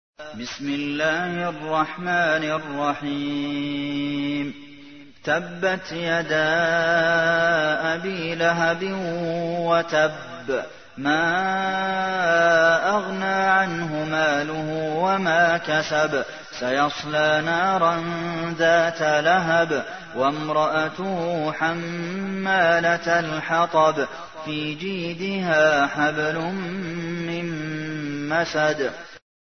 تحميل : 111. سورة المسد / القارئ عبد المحسن قاسم / القرآن الكريم / موقع يا حسين